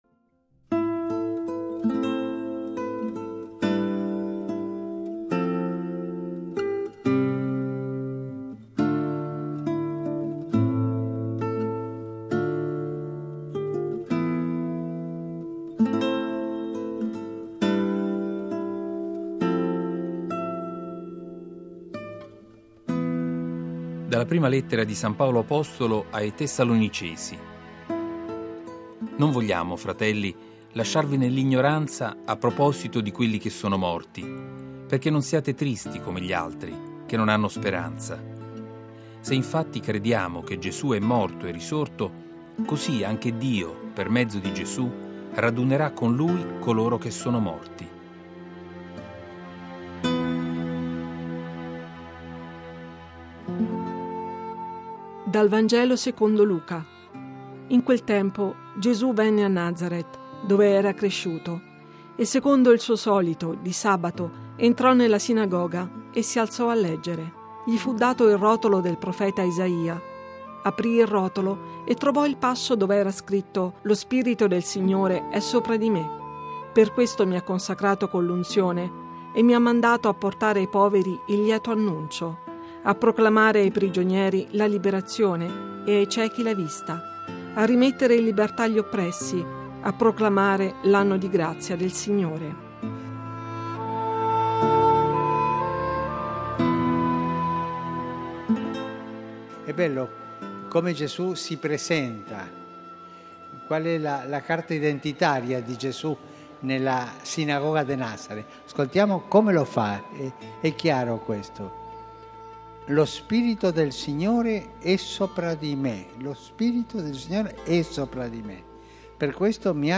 Le letture del giorno (prima e Vangelo) e le parole di Papa Francesco da VaticanNews.